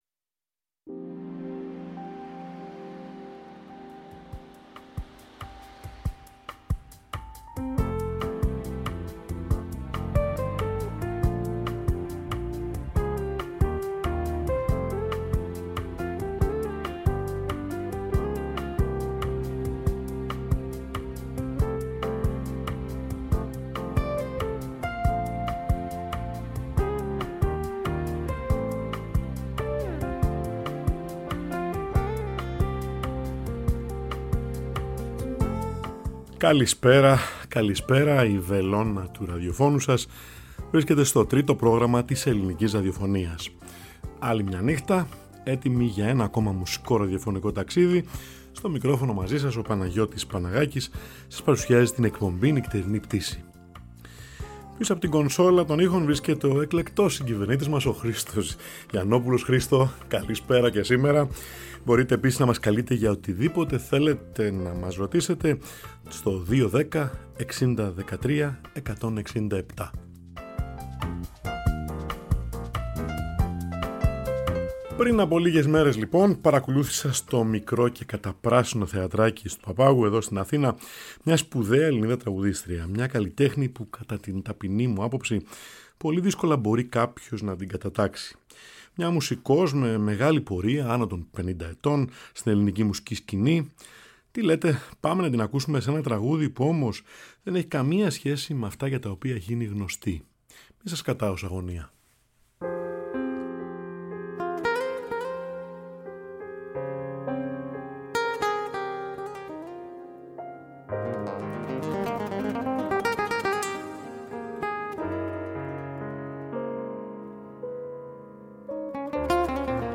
Και αν η Αλέκα Κανελλίδου (μια από τις ελάχιστες Ελληνίδες τραγουδίστριες που μπορούν ΠΡΑΓΜΑΤΙΚΑ να τραγουδήσουν jazz) ζούσε στη Νέα Υόρκη, ποιο θα ήταν το ρεπερτόριο της;
H «Νυχτερινή Πτήση» απογειώνεται κάθε Τρίτη & Πέμπτη μία ώρα πριν από τα μεσάνυχτα, στο Τρίτο Πρόγραμμα 90,9 & 95,6 της Ελληνικής Ραδιοφωνίας